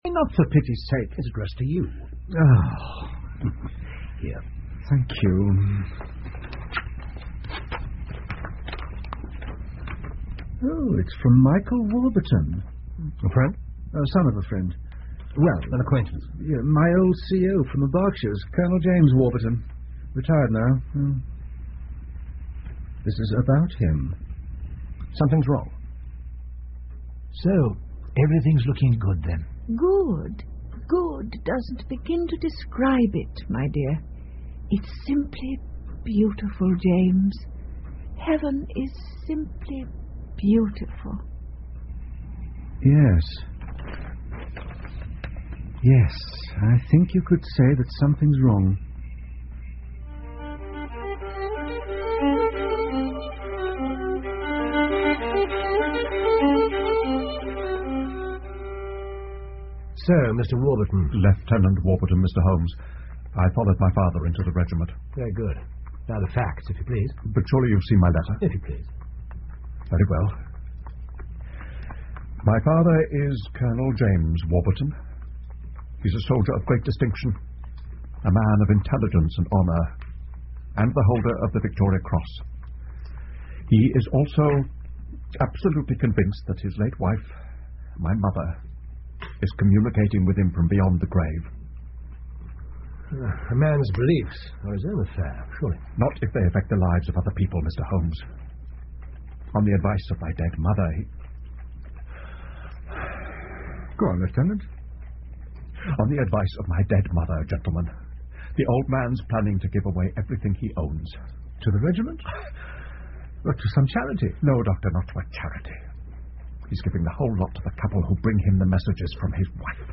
福尔摩斯广播剧 Futher Adventures-The Madness Of Colonel Warburton 2 听力文件下载—在线英语听力室